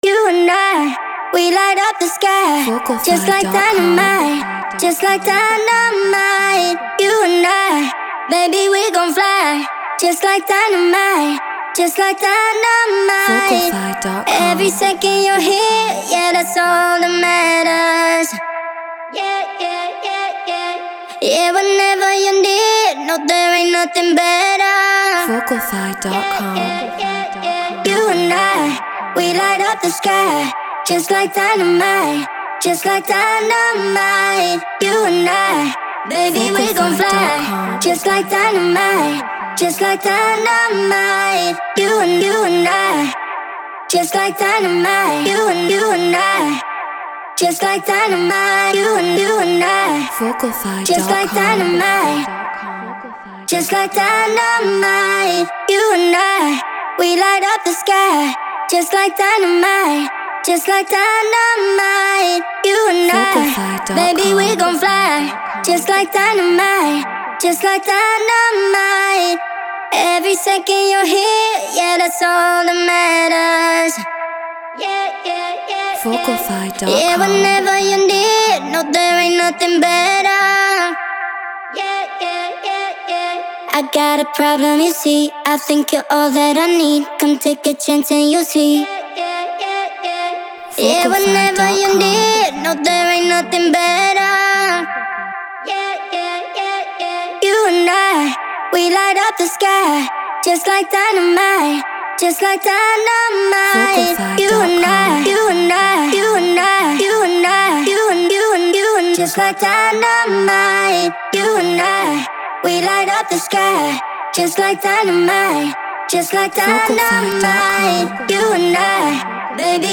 UKG 138 BPM Gmin
Neumann TLM 103 Focusrite Scarlett Pro Tools Treated Room